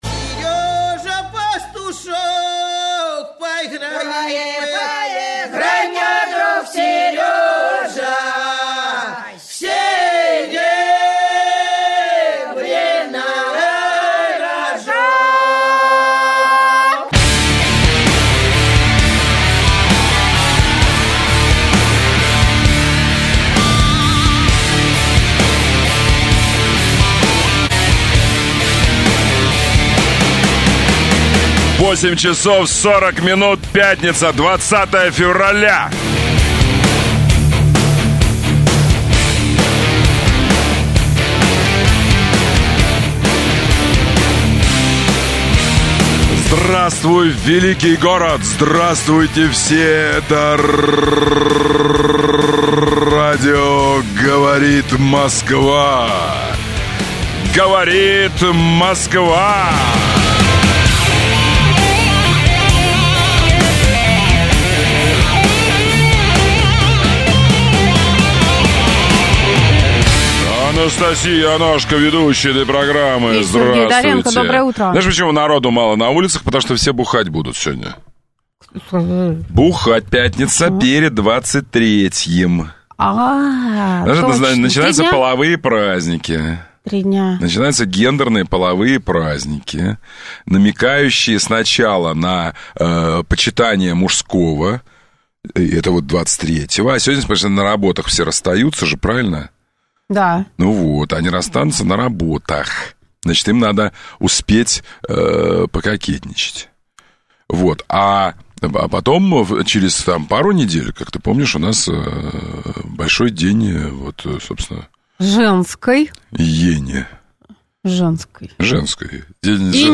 Авторская программа Сергея Доренко. Обсуждение самых актуальных общественно-политических тем, телефонные голосования среди слушателей по самым неоднозначным и острым вопросам, обзоры свежей прессы.